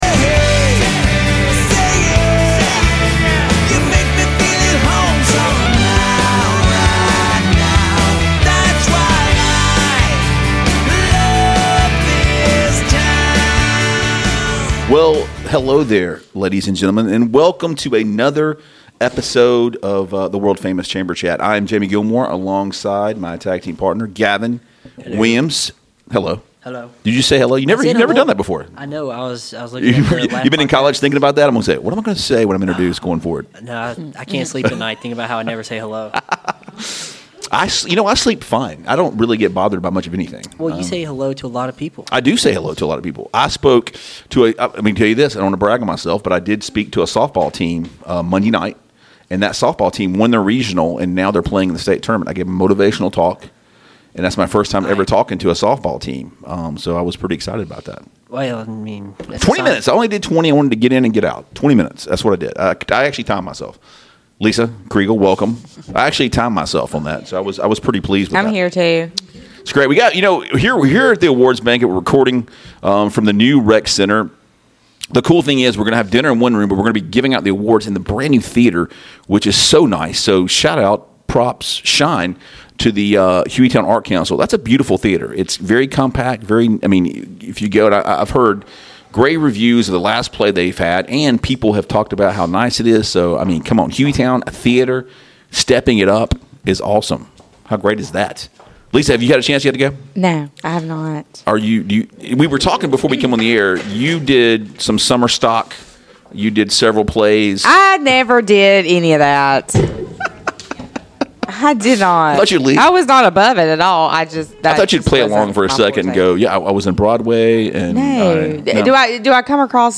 Lets celebrate hueytown's greatest as we are at the Hueytown Chambers Award ceremony. Somewhat behind the scenes of the event we talk about what is to come and our first ever back to back winner.